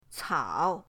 cao3.mp3